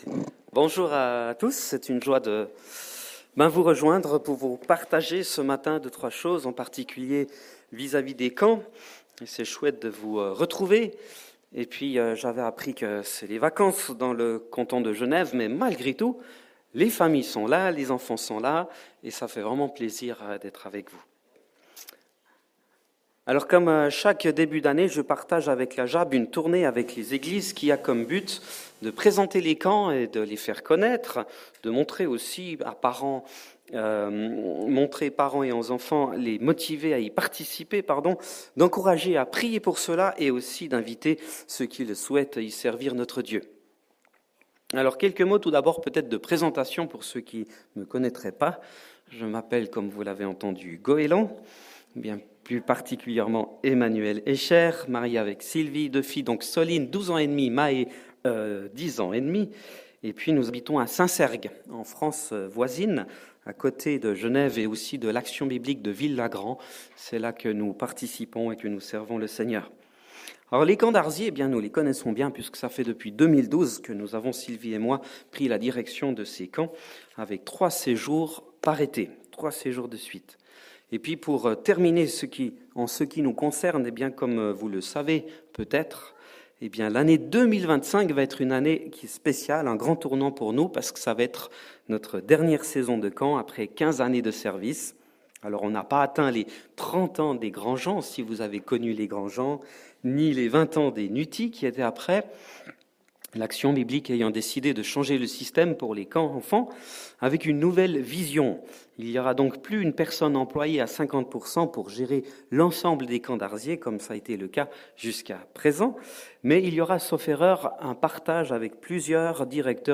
Sermons – EEIG